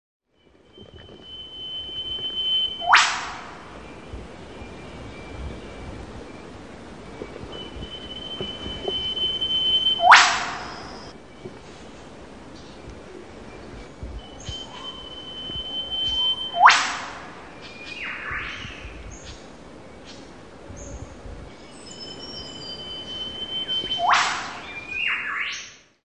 trzaskacz_czarnoczuby.mp3